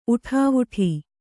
♪ uṭhāvuṭhi